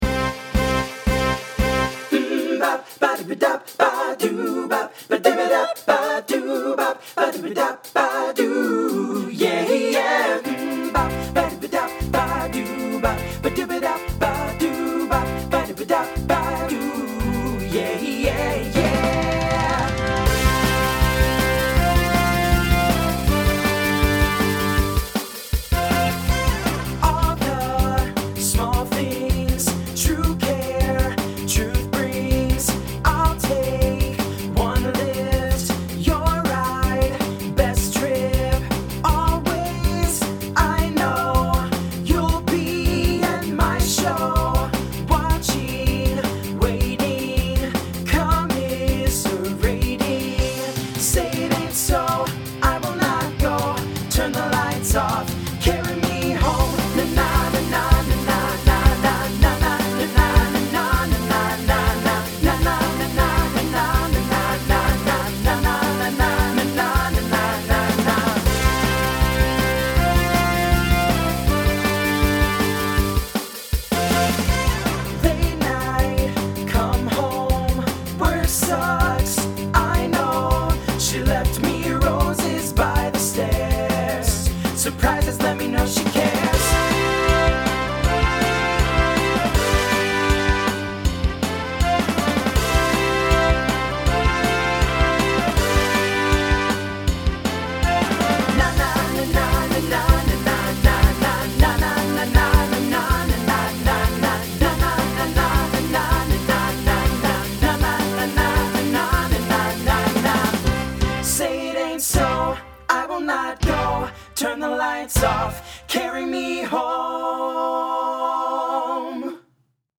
Voicing TTB Instrumental combo Genre Pop/Dance , Rock